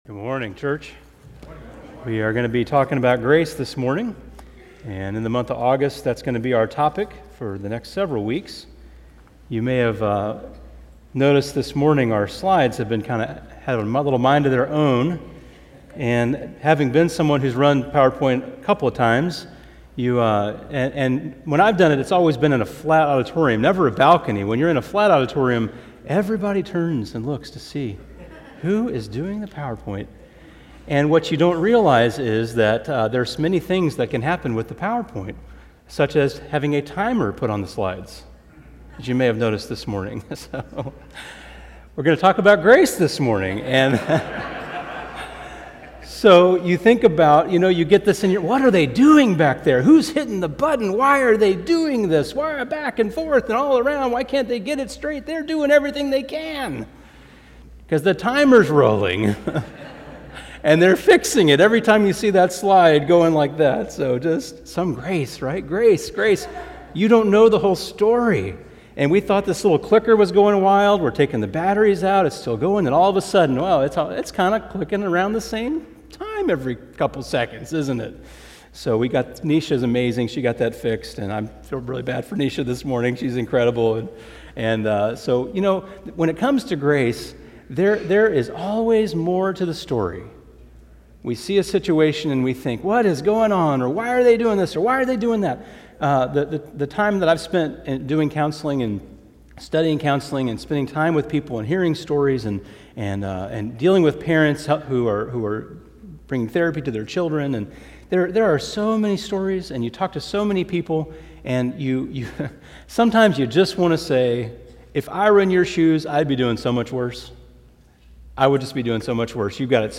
Here is a link to my sermon from last Sunday on grace.